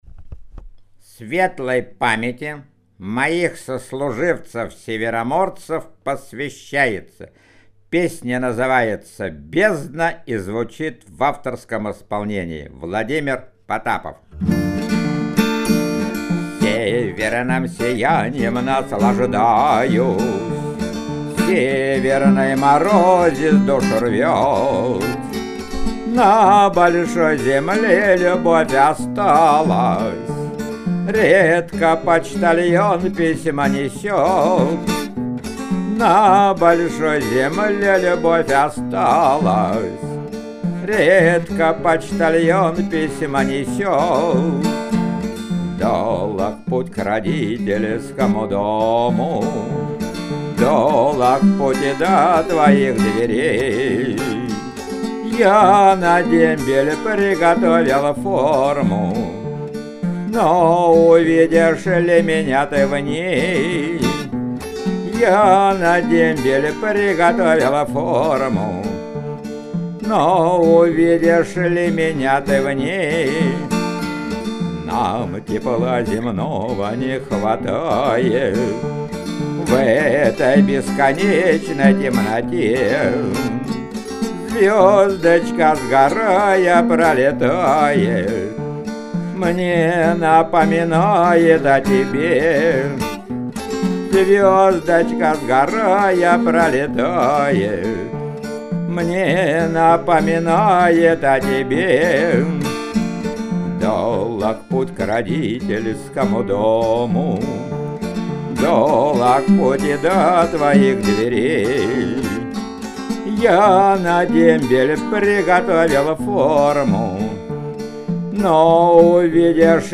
Главная / Computer & mobile / Мелодии / Марши